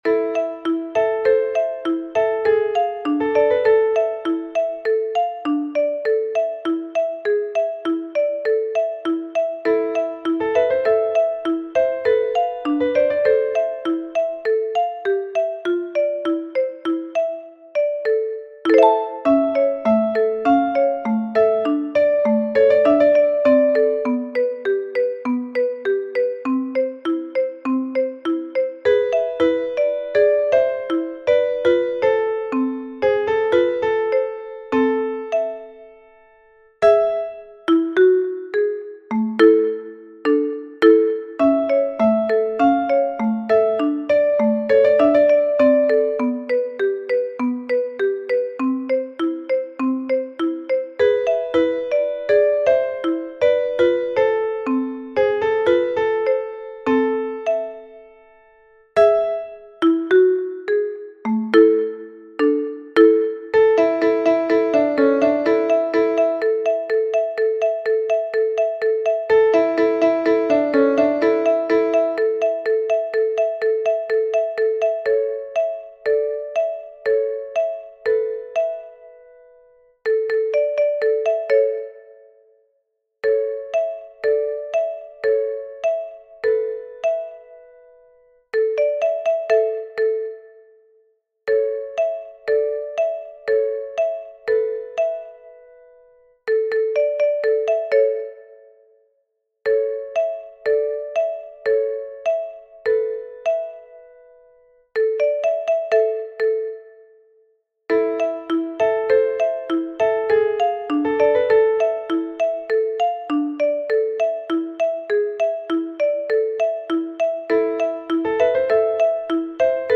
Now let's do the same but this time there are some silent bars.
Here you have got the sound file with the silent bars.
Hungarian_Dance_No._5_in_G_Minor_silencios.mp3